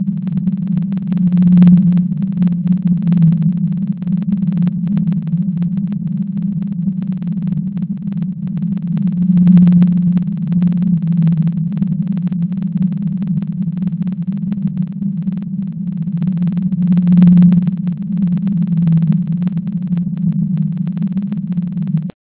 Blue_whale_atlantic3.ogg